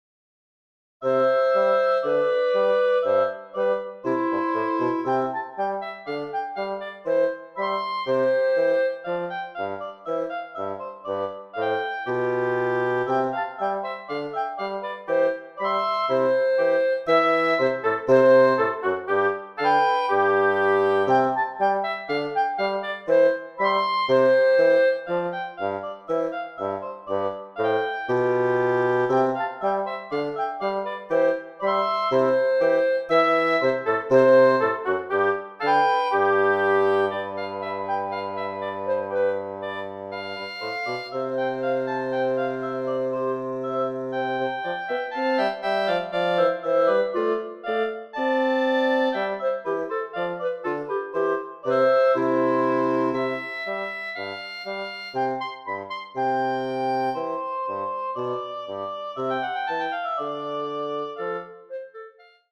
Voicing: Woodwind Trio